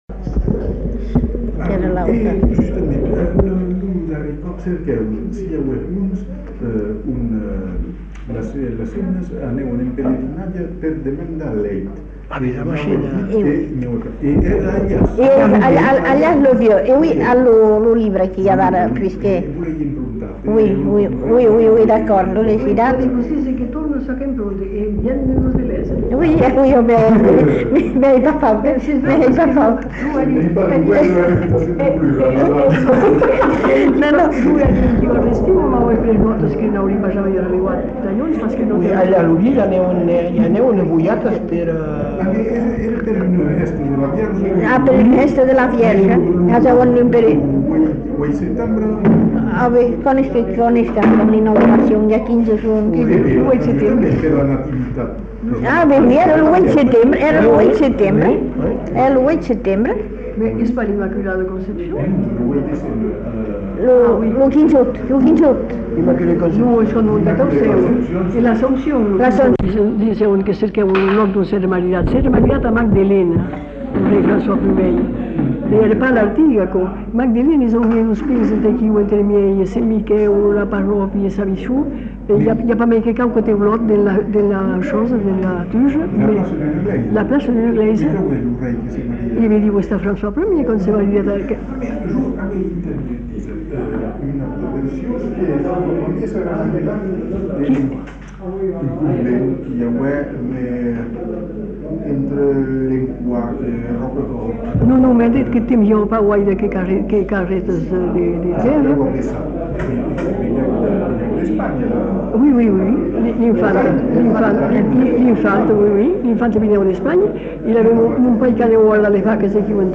Aire culturelle : Bazadais
Lieu : Bazas
Genre : témoignage thématique